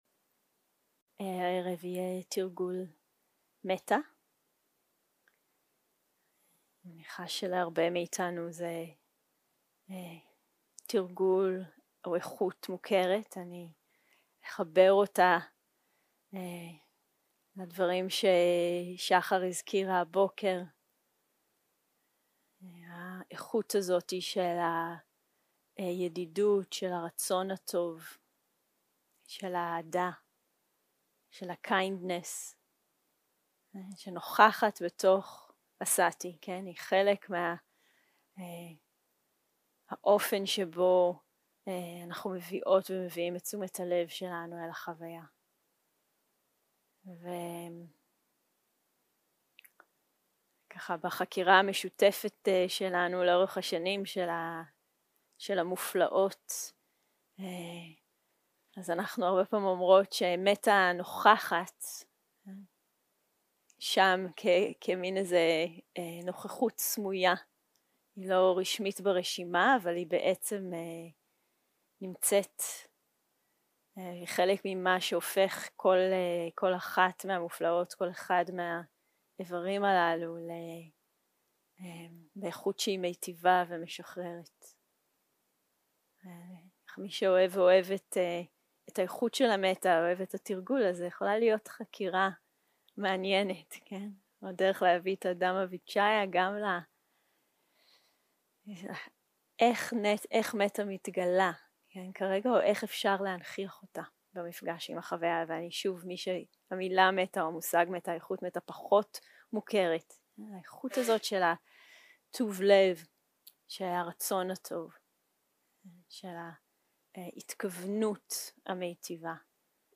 יום 2 - הקלטה 4 - ערב - מדיטציה מונחית - מטא - מבוא ותרגול
יום 2 - הקלטה 4 - ערב - מדיטציה מונחית - מטא - מבוא ותרגול 0:00 0:00 סוג ההקלטה: Dharma type: Guided meditation שפת ההקלטה: Dharma talk language: Hebrew